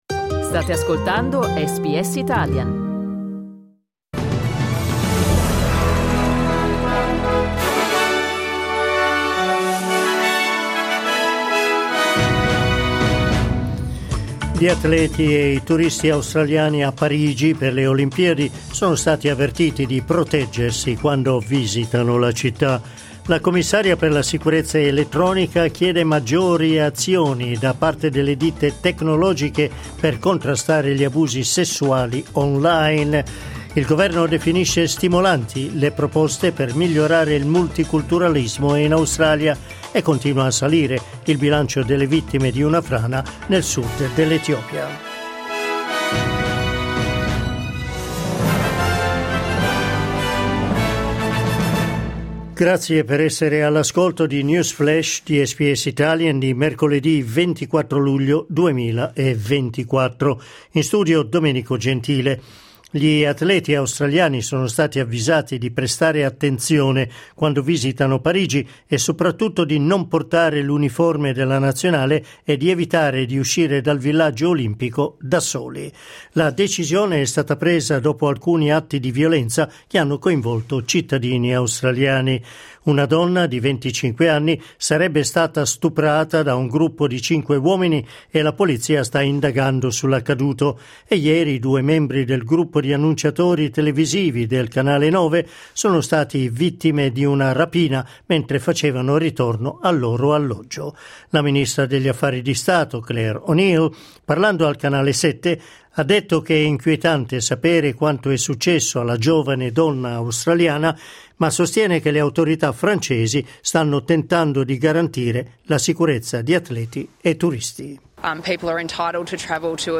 News flash mercoledì 24 luglio 2024